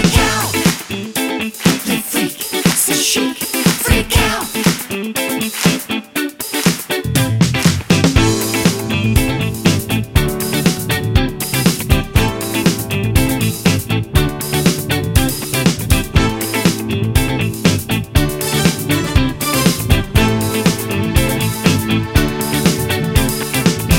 No Main Guitar Disco 3:34 Buy £1.50